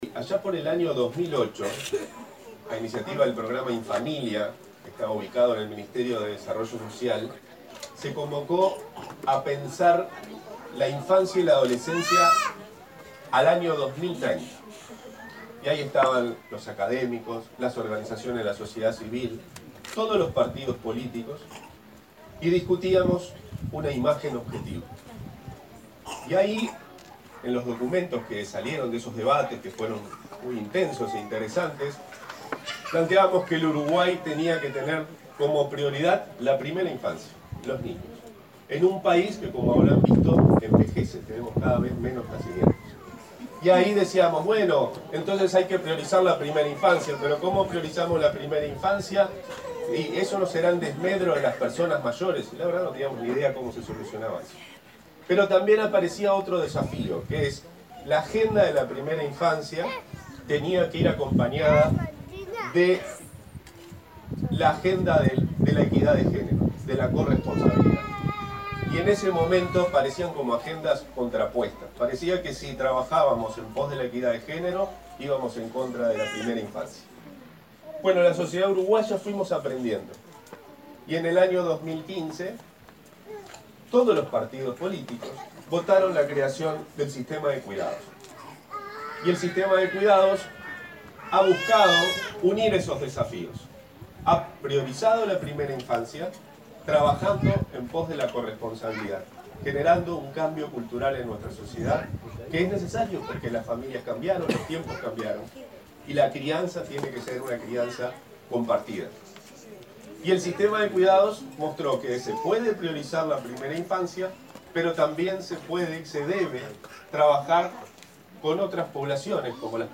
“En 2015 todos los partidos políticos votaron la creación del Sistema de Cuidados, que ha buscado unir los desafíos de priorizar la agenda de la primera infancia con la equidad de género y la corresponsabilidad de hombres y mujeres en el cuidado de los niños, lo que generó un cambio cultural”, dijo el director de Infancia, Gabriel Corbo, al inaugurar el CAIF Nuevo Ellauri para más de 100 niños en Casavalle, Montevideo.